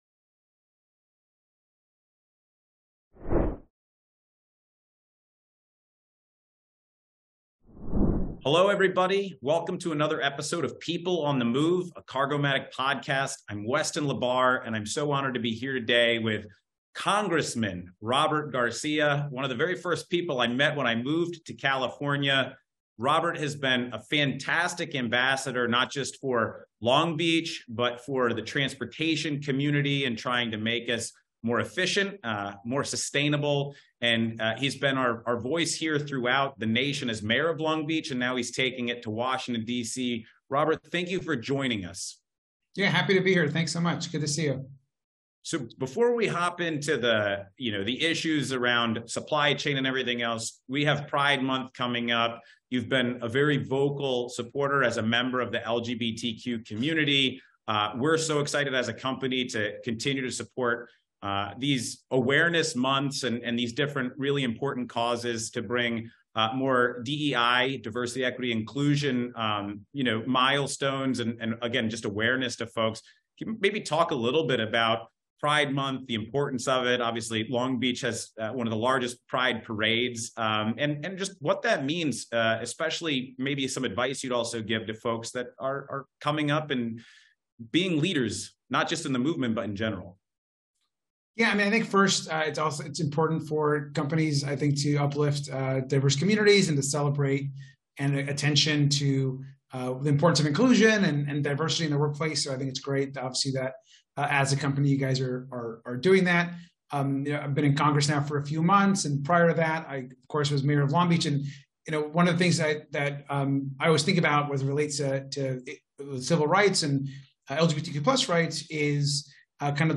People on the Move by Cargomatic - Fireside Chat featuring